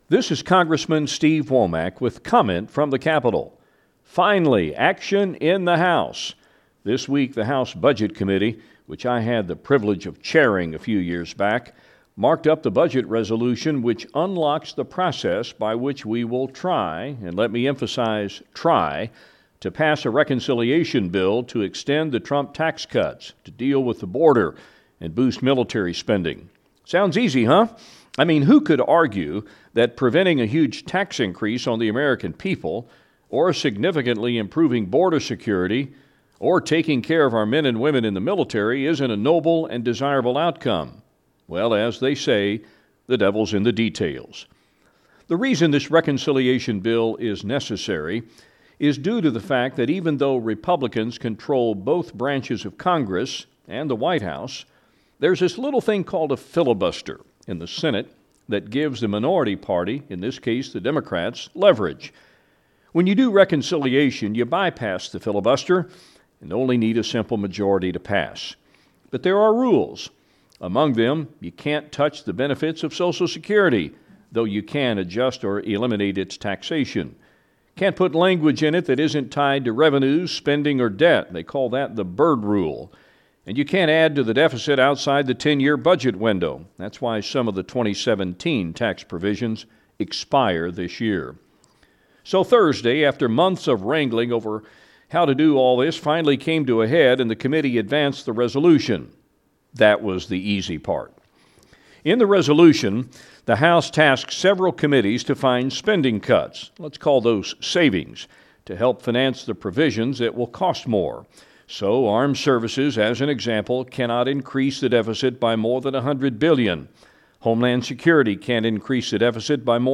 In this week’s recap of the activity in Washington, Arkansas’ District 3 U.S. Rep. Steve Womack gives updates on the budget reconciliation process, a bill he reintroduced (the Local Radio Freedom Act) and his continued position as Chairman of the West Point Board of Visitors. Here’s Congressman Womack with his Comment from the Capitol.